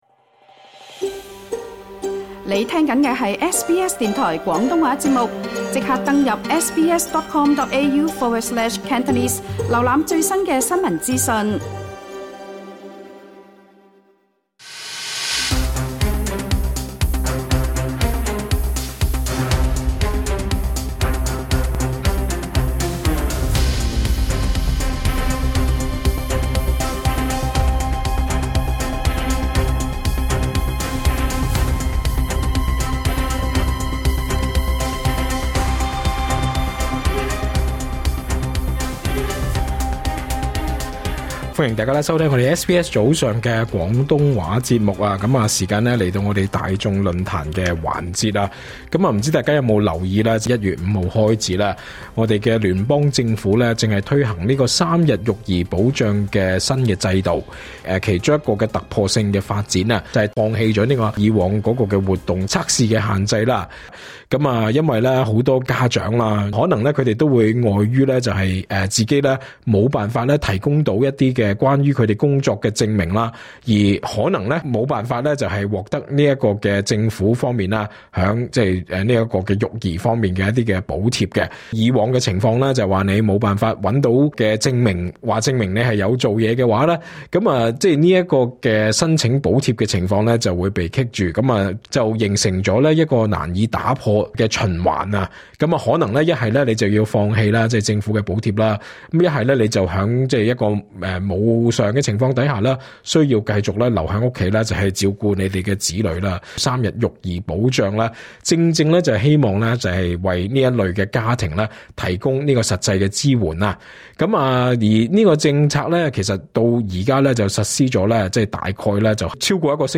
詳情請收聽足本訪問。